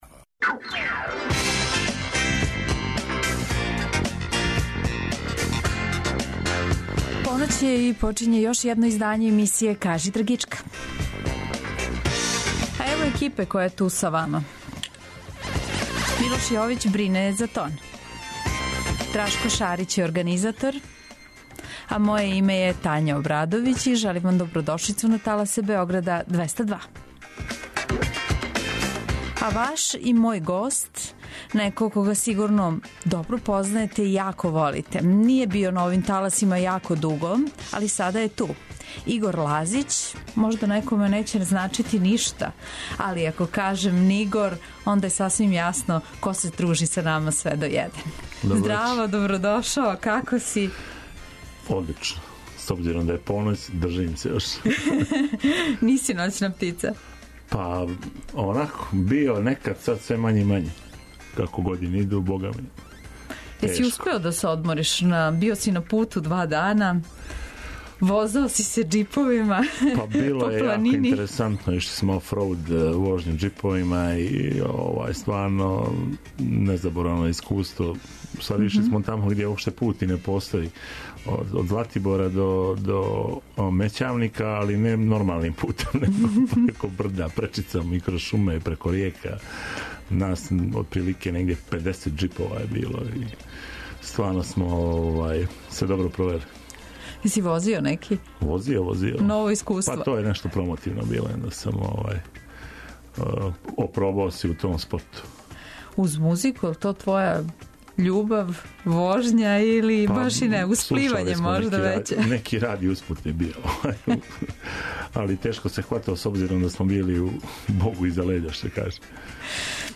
Музички гост се, у сат времена програма, представља слушаоцима својим ауторским музичким стваралаштвом, као и музичким нумерама других аутора и извођача које су по њему значајне и које вам препоручују да чујете.